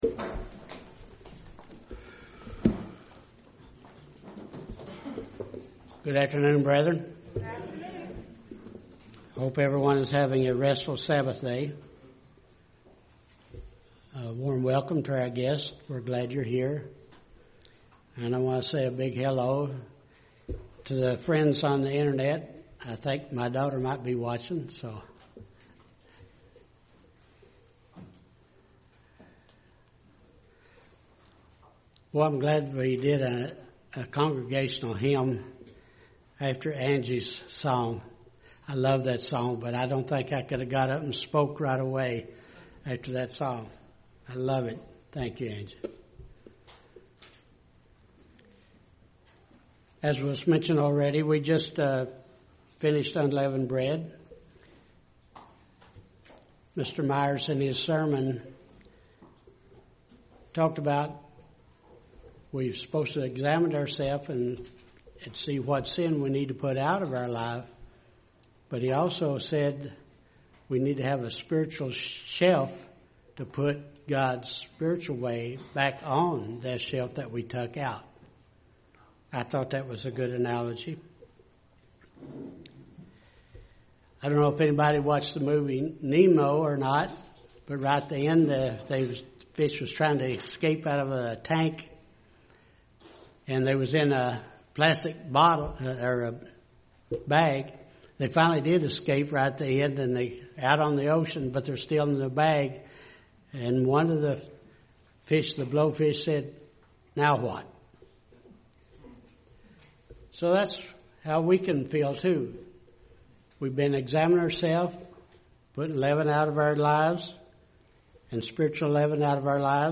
Sermons
Given on Apr 27, 2019 by Given in Dayton, OH Downloads Downloads Download Audio To download a file, right-click (or long press) a link above and choose Save As .